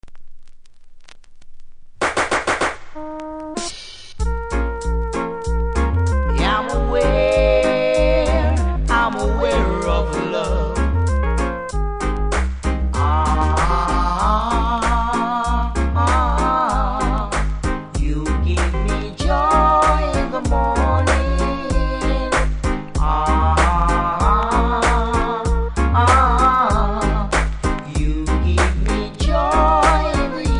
REGGAE 80'S
多少ノイズ感じますがプレイは問題無いレベル。